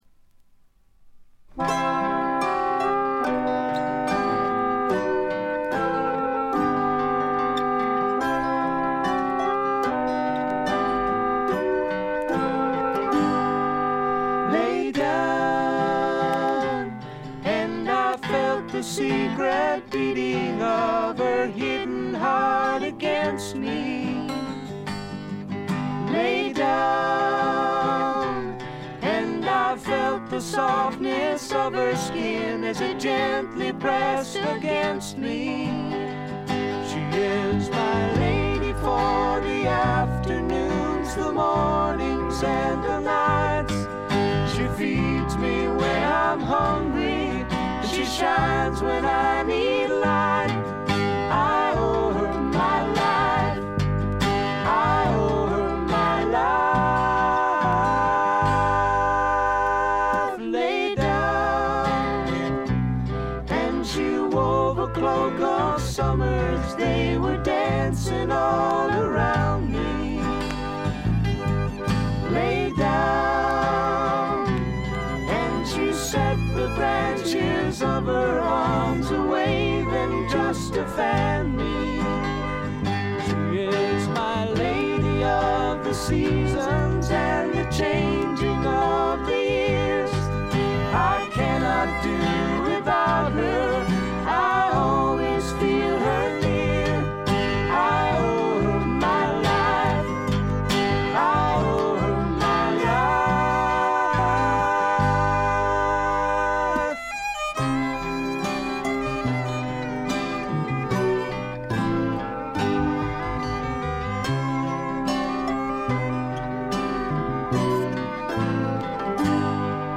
ホーム > レコード：英国 SSW / フォークロック
軽微なチリプチ少々。
静と動の対比も見事でフォークロック好きにとってはこたえられない作品に仕上がっています！
試聴曲は現品からの取り込み音源です。